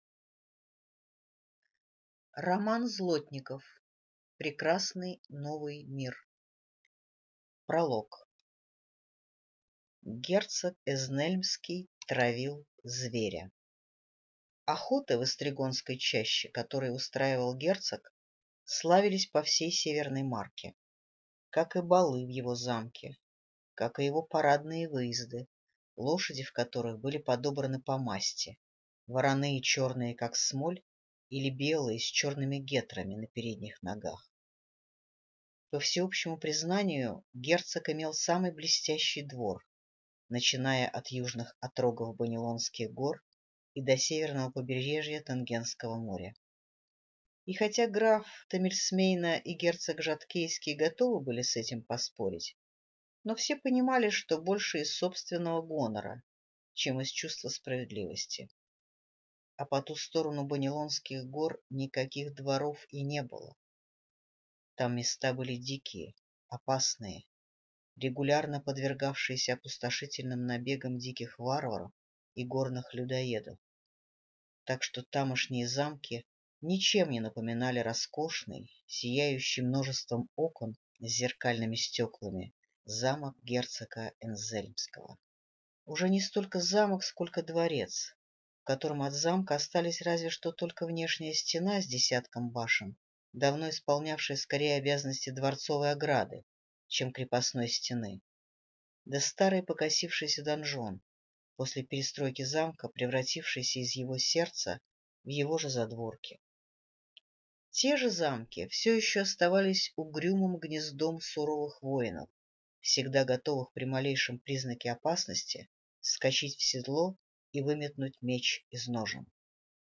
Прослушать и бесплатно скачать фрагмент аудиокниги